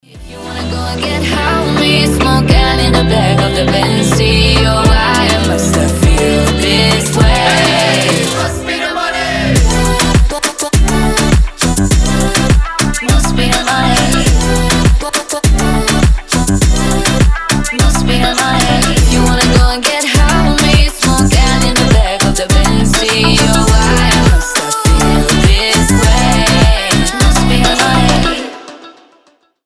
• Качество: 320, Stereo
Moombahton